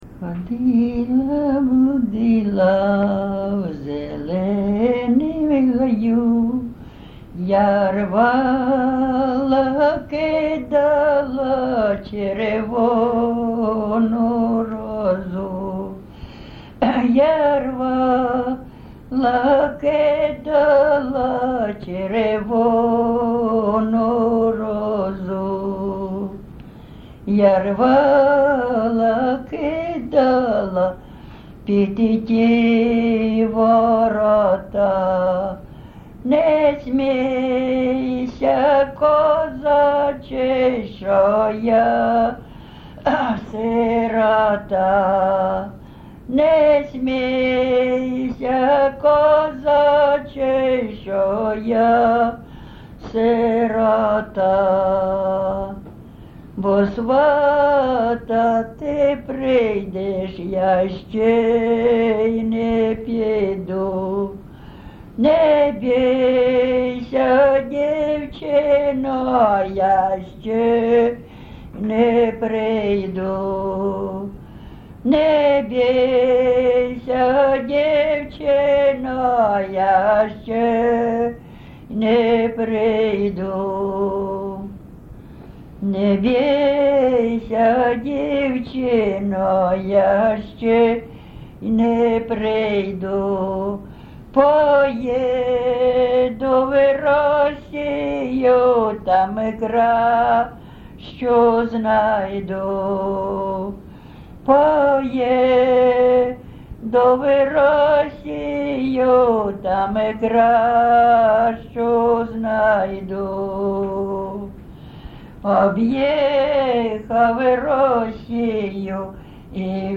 ЖанрПісні з особистого та родинного життя
Місце записус-ще Олексієво-Дружківка, Краматорський район, Донецька обл., Україна, Слобожанщина